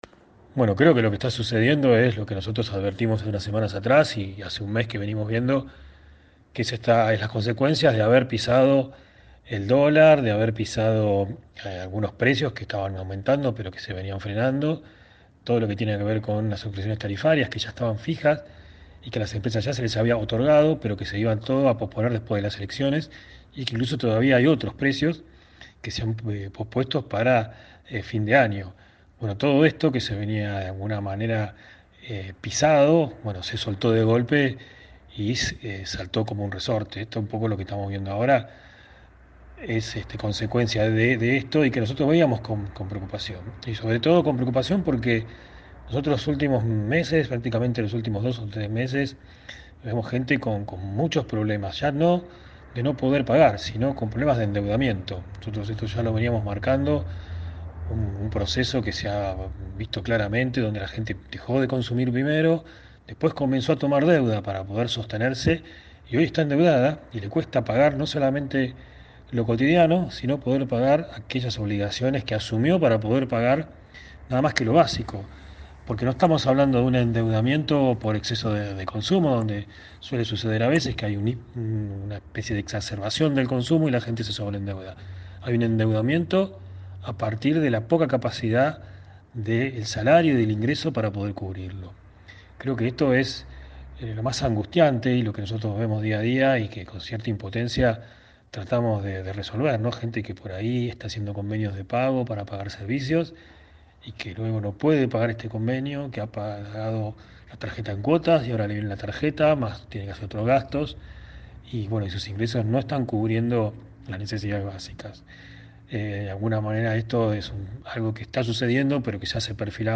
se expresó en el programa radial Bien Despiertos